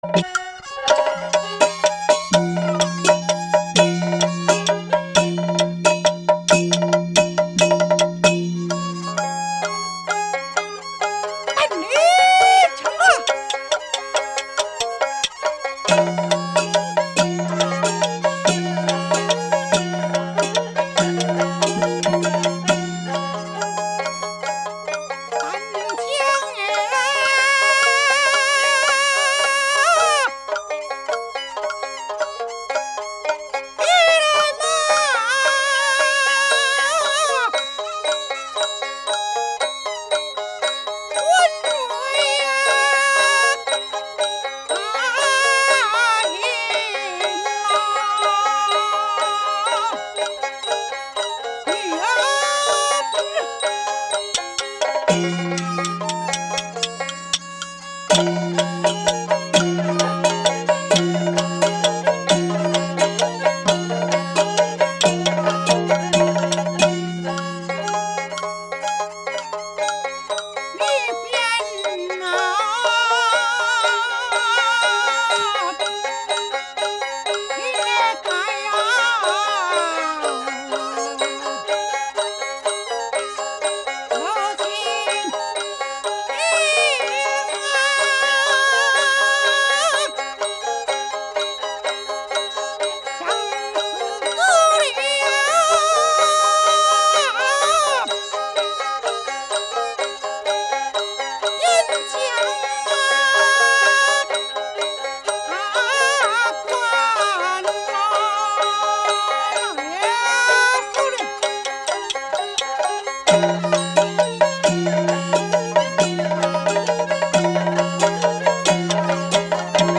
戲曲 - 羅成寫書選段1（緊中慢） | 新北市客家文化典藏資料庫